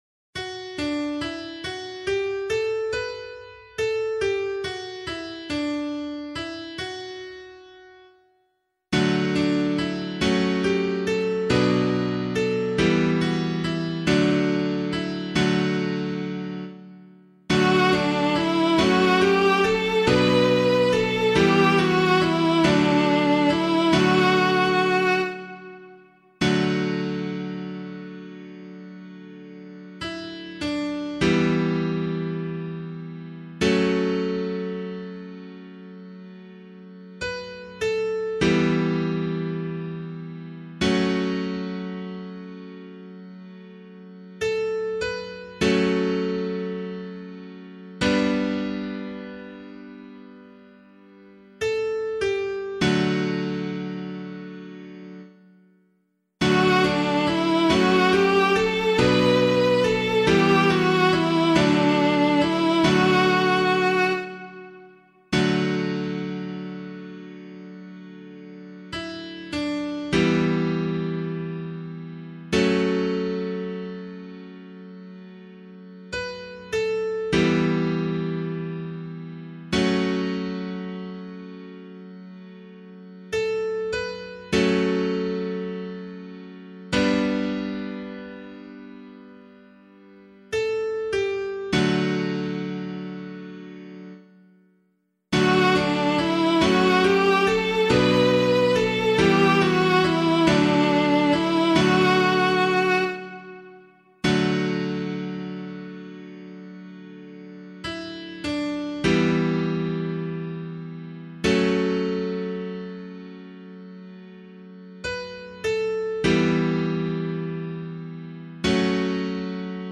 031 Pentecost Vigil Psalm 1 [LiturgyShare 5 - Oz] - piano.mp3